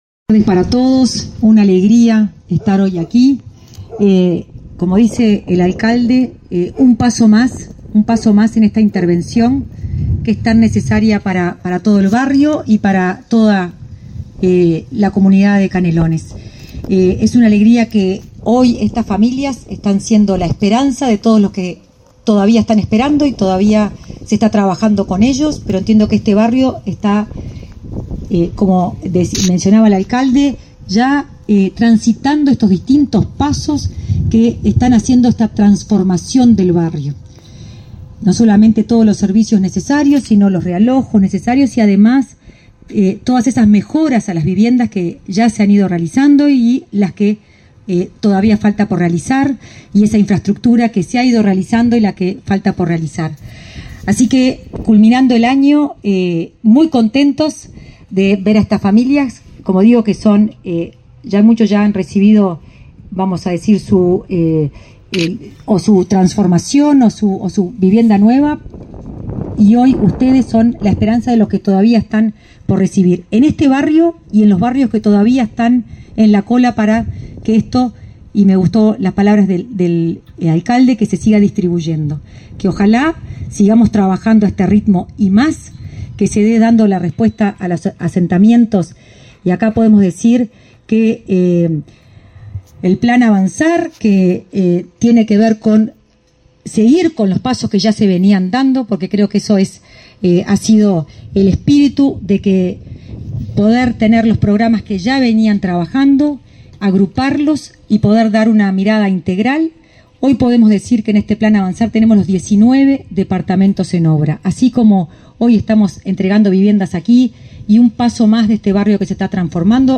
Acto de entrega de viviendas del Plan Avanzar en Canelones
Participaron en el evento el subsecretario del MVOT, Tabare Hackenbruch, y la directora de Integración Social, Florencia Arbeleche.